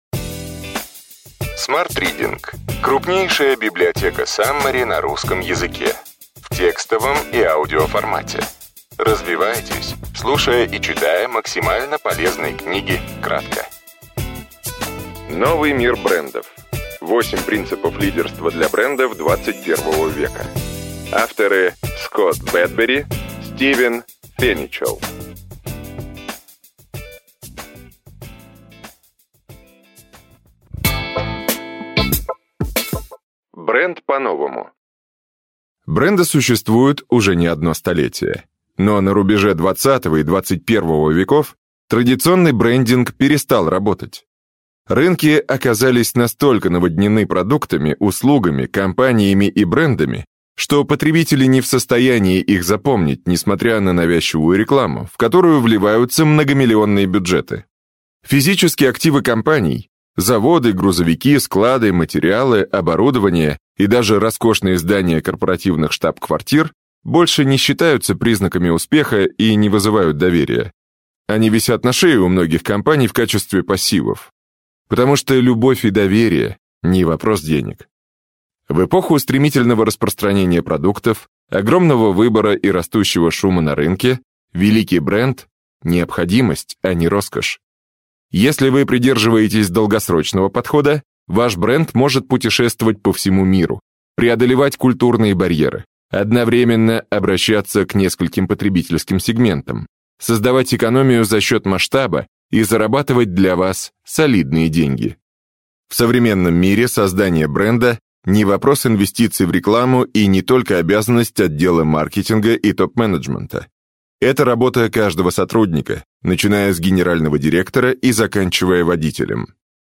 Аудиокнига Новый мир брендов. 8 принципов лидерства для брендов ХХI века. Скотт Бедбери, Стивен Феничелл. Саммари | Библиотека аудиокниг